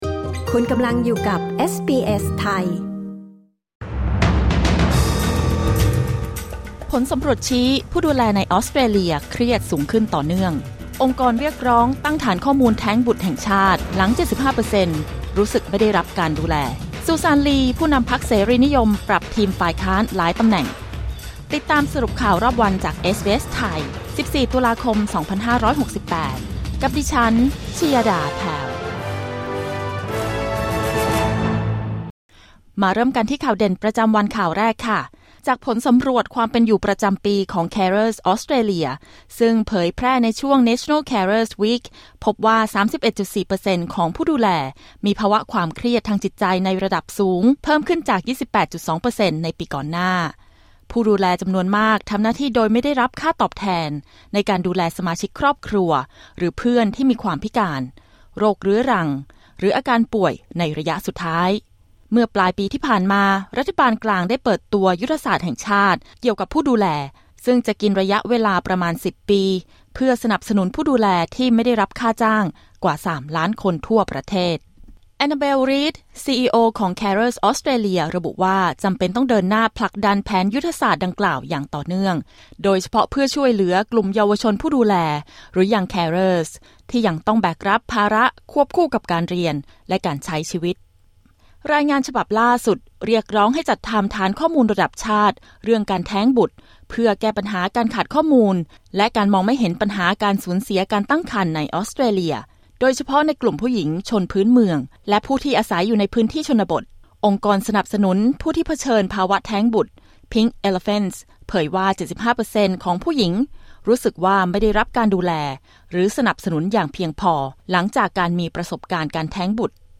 สรุปข่าวรอบวัน 14 ตุลาคม 2568